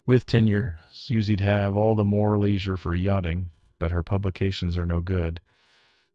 text-to-speech voice-cloning